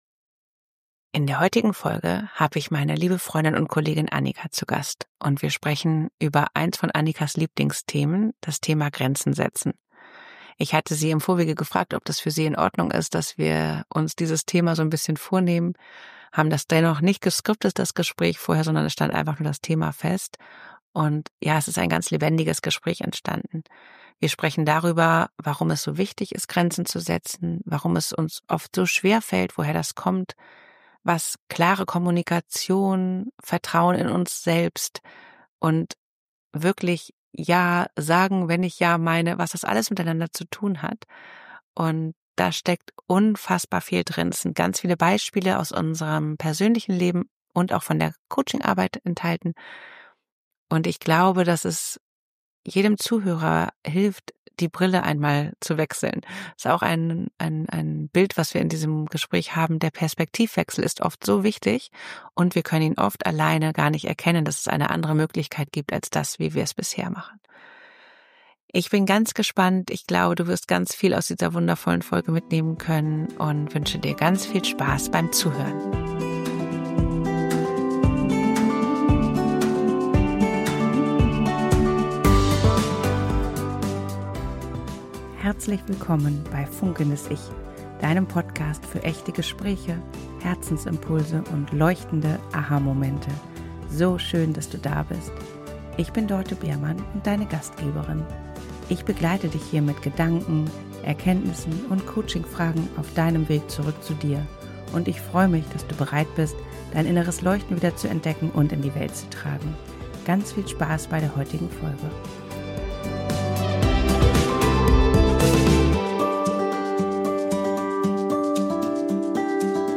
Diese Folge ist im Grunde mehr als ein Gespräch – sie ist fast ein kleiner Kurs. Mit persönlichen Beispielen, ehrlichen Erkenntnissen und ganz vielen Aha-Momenten.